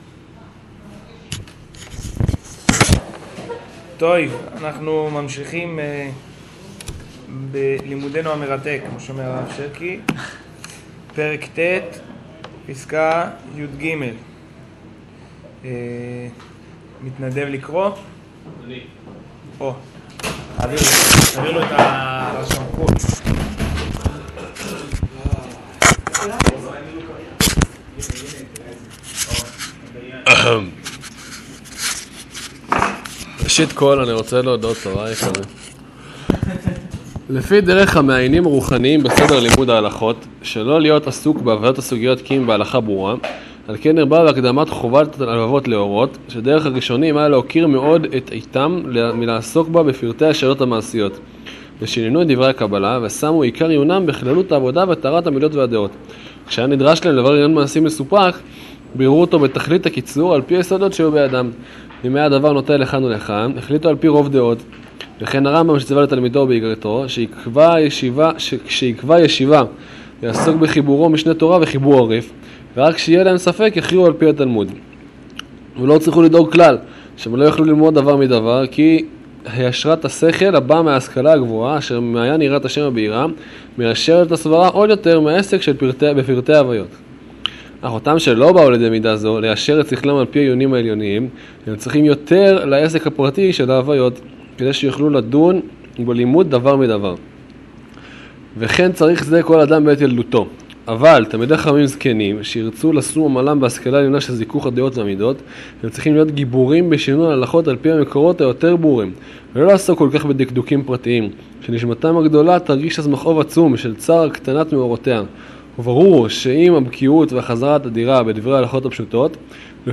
שיעור